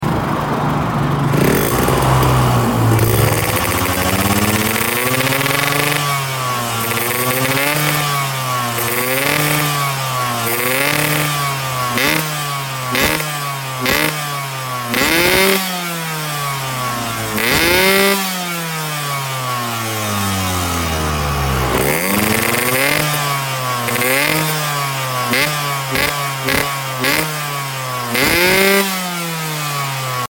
Cek sound knalpot altech c17, sound effects free download